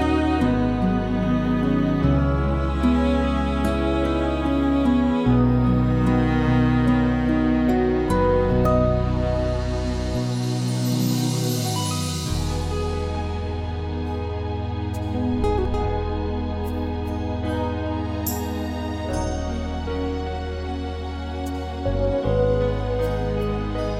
no Backing Vocals Duets 3:52 Buy £1.50